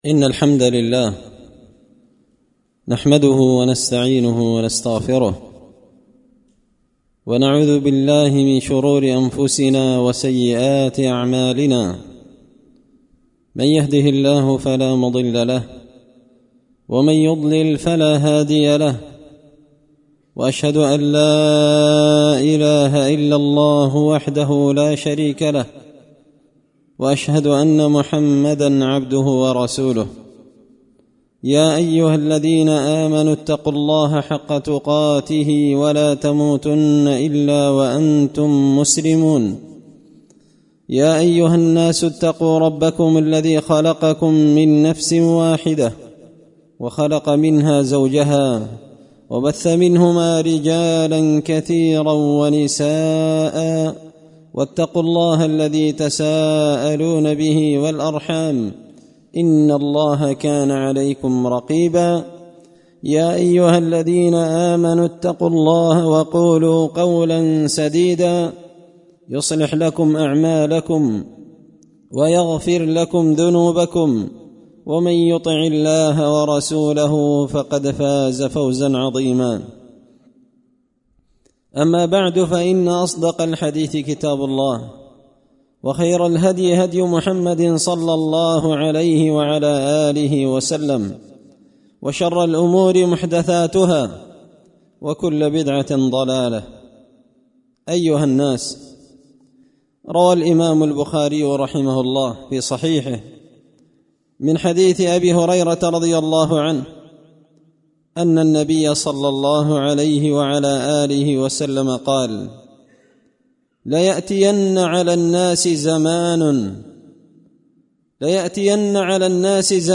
خطبة جمعة بعنوان – من أسباب عدم التورع عن الحرام
دار الحديث بمسجد الفرقان ـ قشن ـ المهرة ـ اليمن